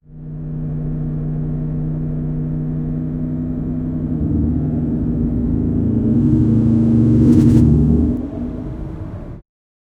som de dentro de carro com motor v12 biturbo+ passando marcha em alta velocidade até 300km
som-de-dentro-de-carro-u6fzjhgc.wav